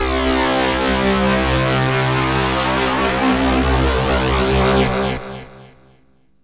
SYNTH LEADS-1 0014.wav